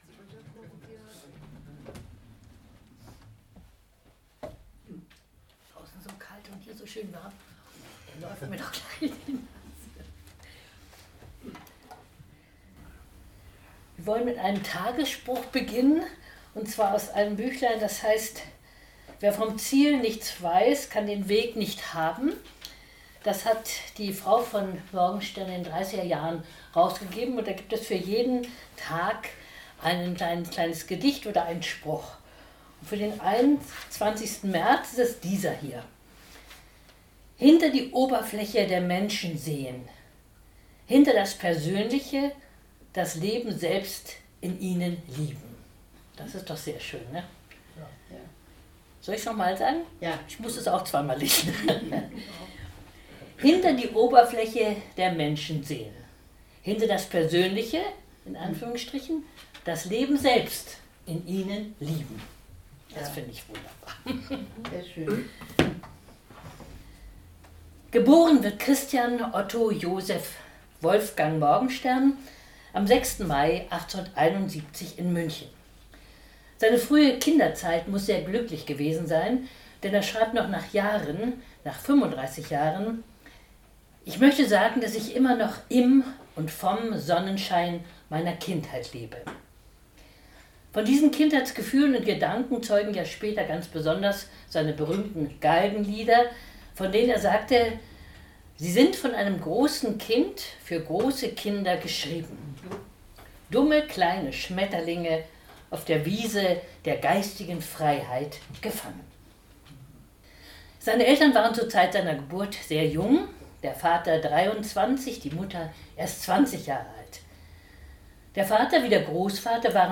Mitschnitt einer öffentlichen Veranstaltung (MP3, Audio)